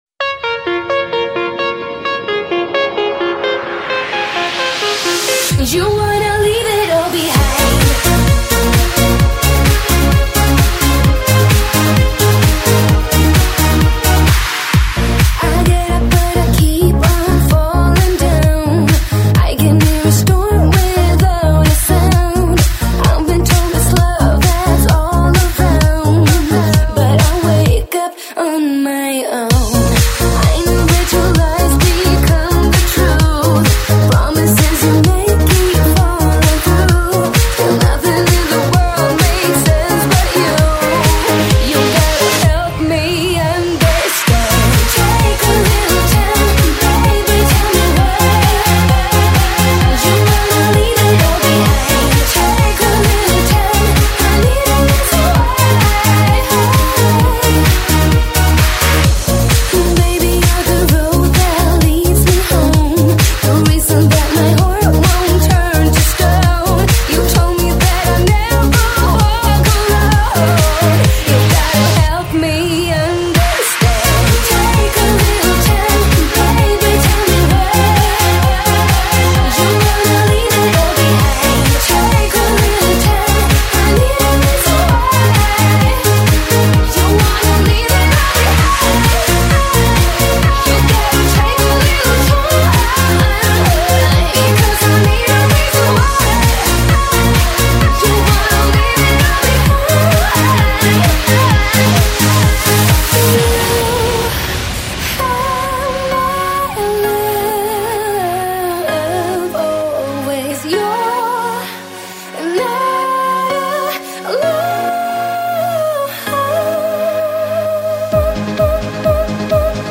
Стиль: Dance